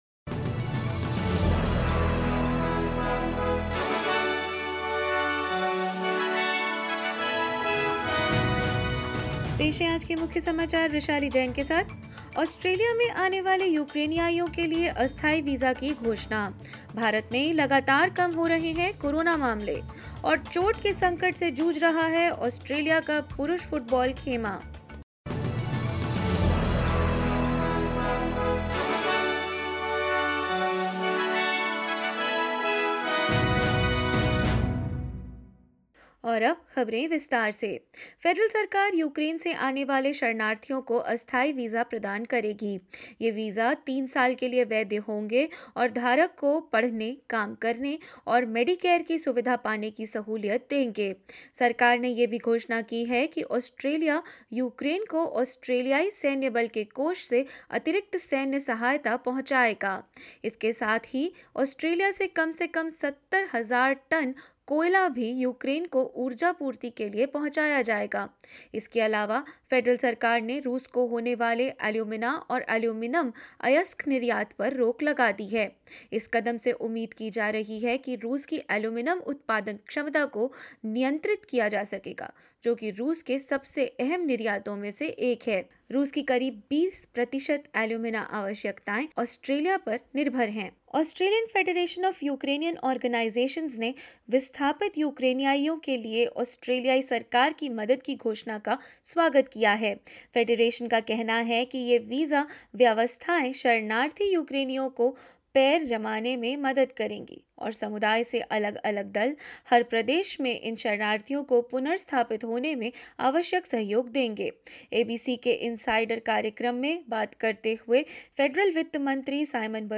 In this latest SBS Hindi bulletin: Federal government has announced temporary humanitarian visa for Ukrainians who have arrived in Australia; Coronavirus cases continue to decline in India; An injury scare for the Socceroos ahead of must-win World Cup qualifying matches and more news.
hindi_news_2003.mp3